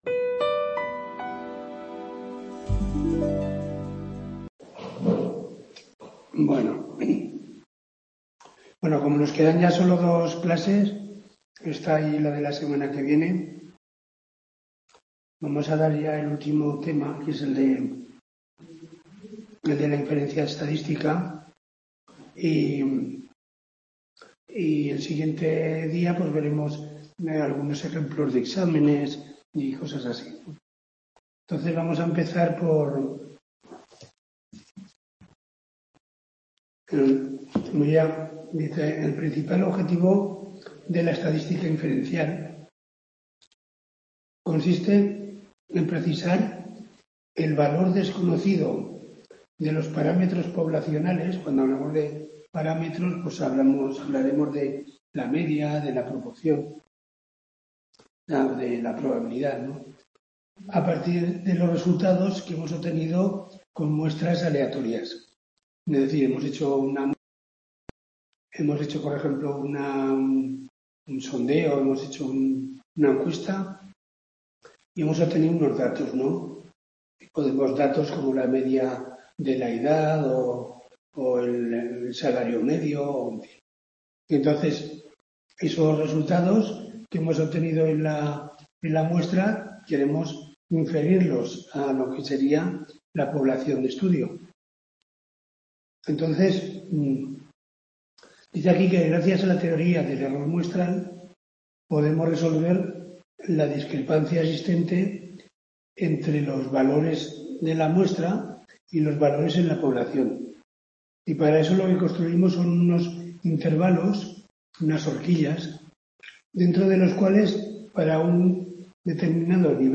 TUTORÍA